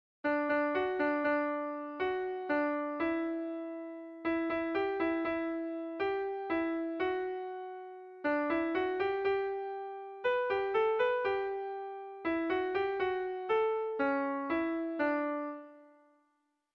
Kontakizunezkoa
Kopla handia
A-B-C